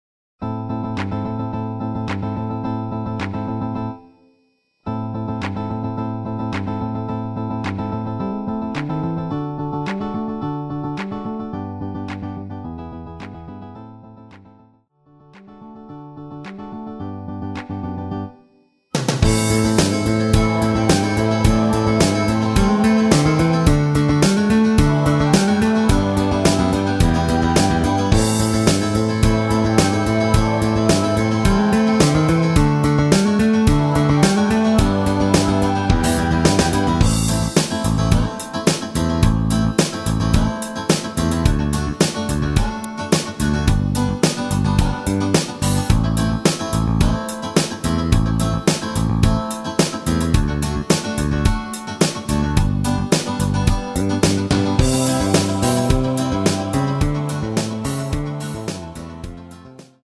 MR입니다.
Am
앞부분30초, 뒷부분30초씩 편집해서 올려 드리고 있습니다.